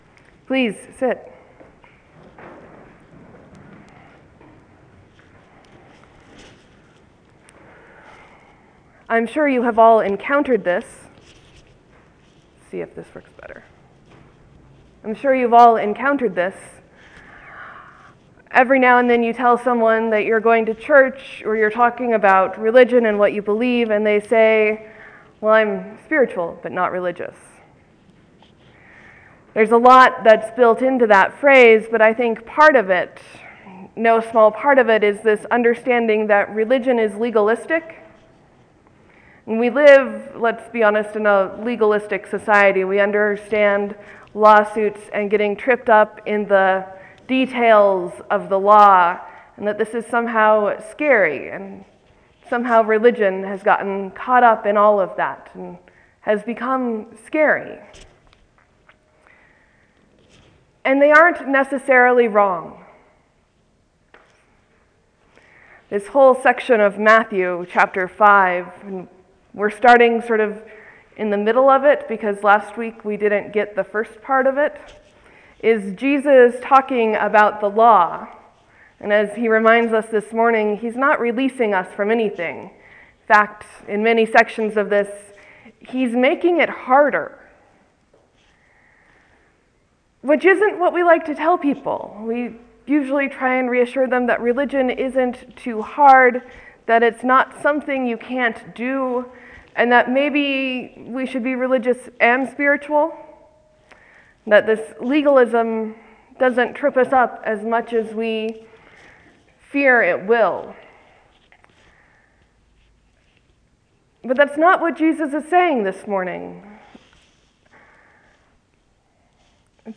Jots and Tittles, sermon for Epiphany 5 2014